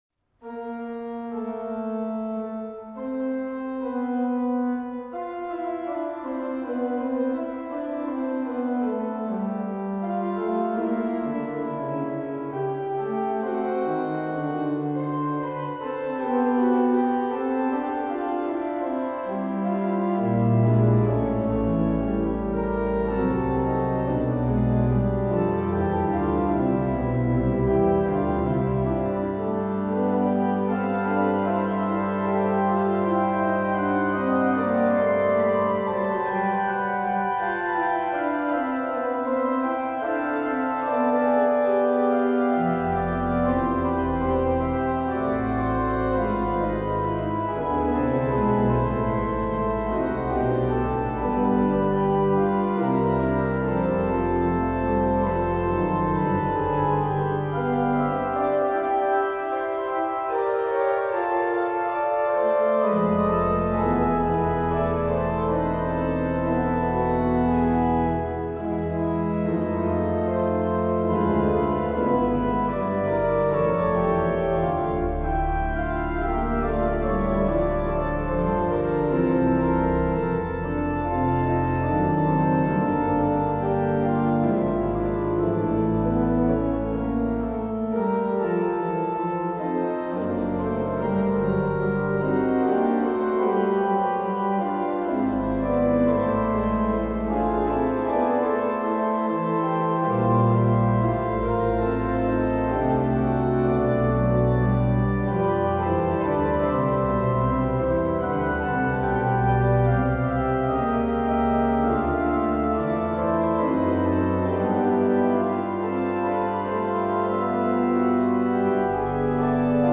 Die Orgel der Heilig-Geist-Kirche